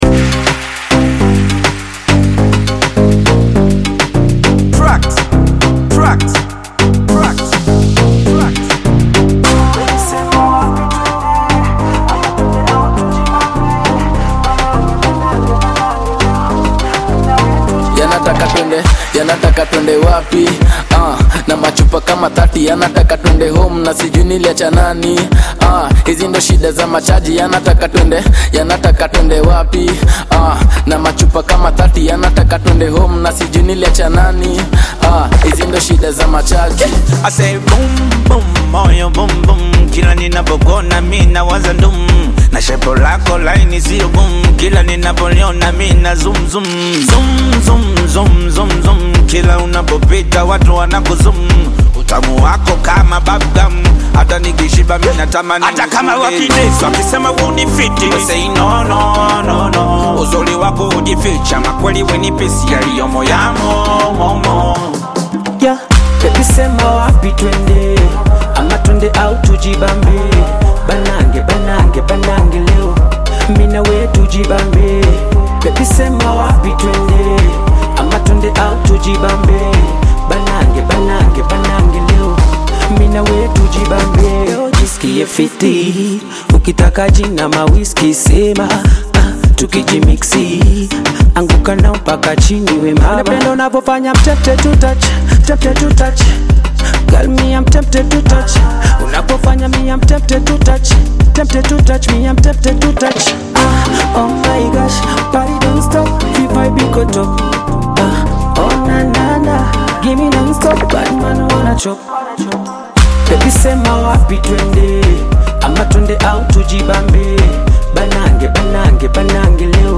vibrant Afro-Beat/Bongo Flava collaboration
upbeat rhythms, expressive Swahili lyrics and catchy hooks
Genre: Bongo Flava